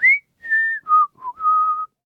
Kibera-Vox_Hum_kr_b.wav